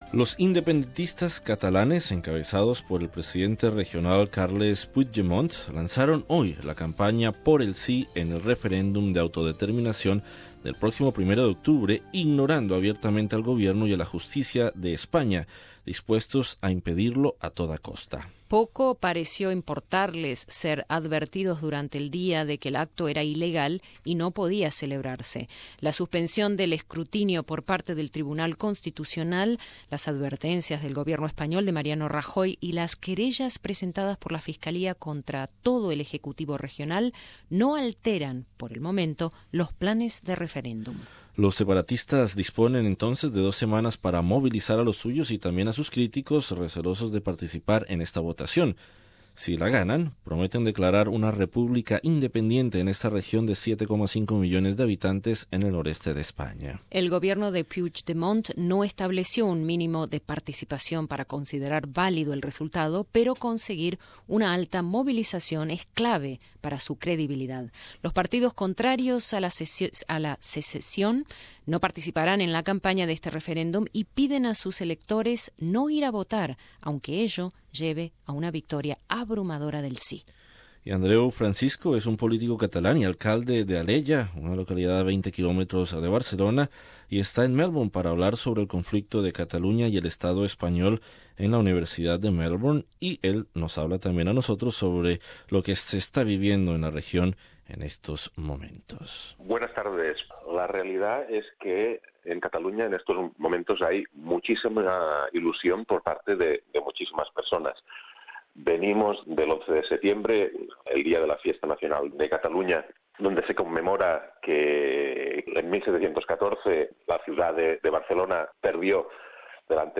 Entrevista con Andreu Francisco, político Catalán y alcalde de Alella , a 20 km de Barcelona, quien se encuentra en Melbourne.